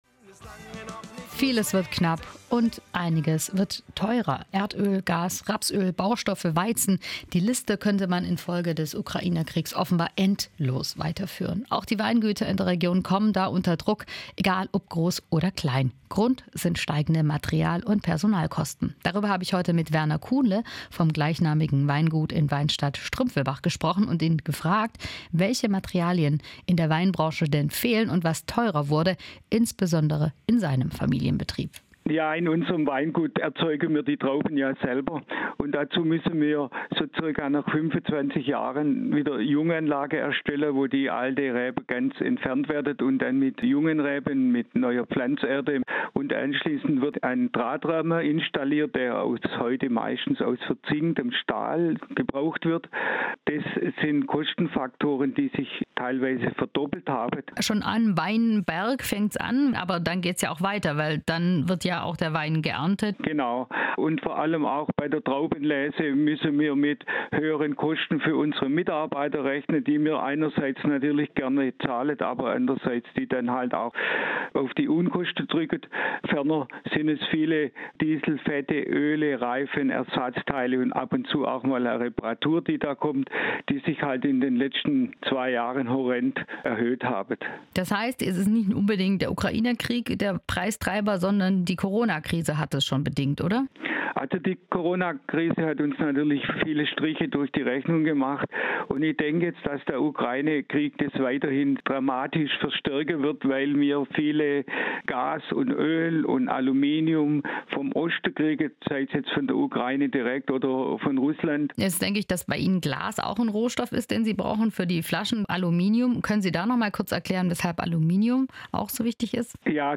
Interview im SWR4 Radio über die enormen Preissteigerungen